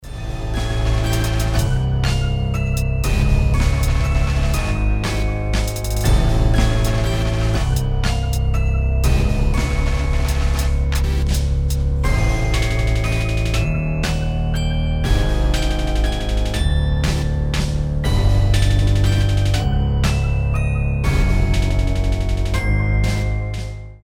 • Качество: 320, Stereo
пугающие
страшные
жуткие